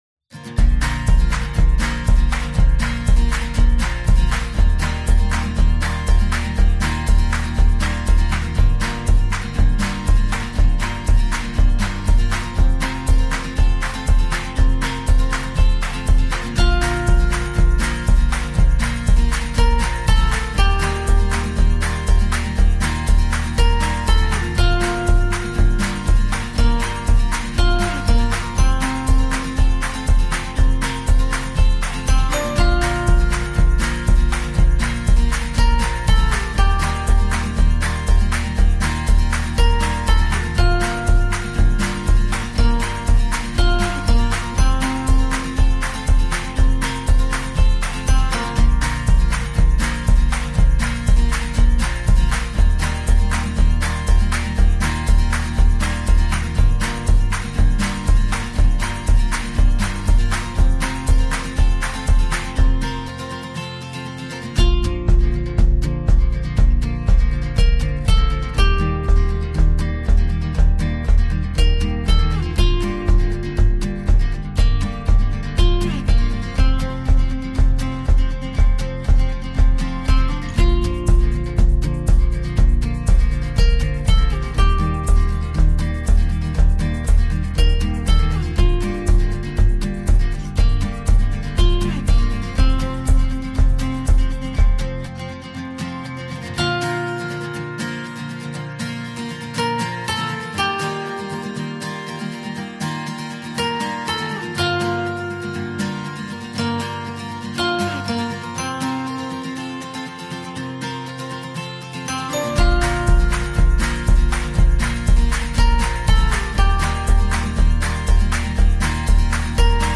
دانلود اهنگ بی کلام برای ساخت کلیپ کودکانه